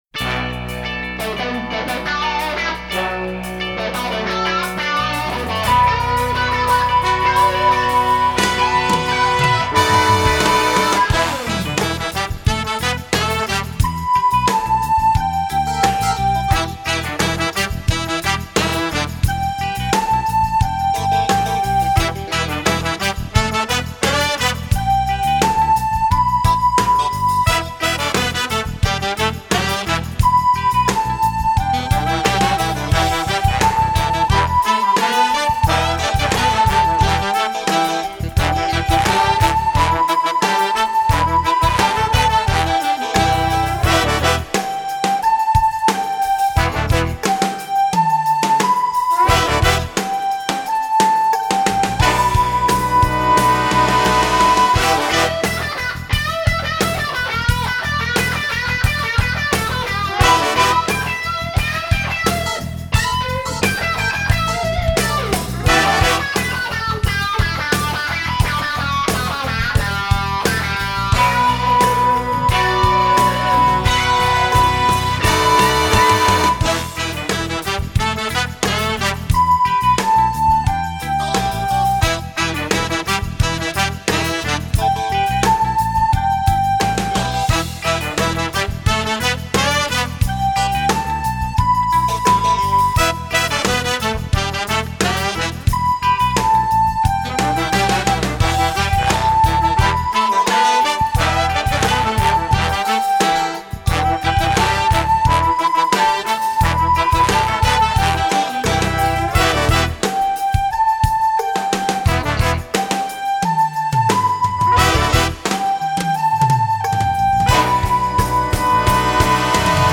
Voicing: Recorder Collection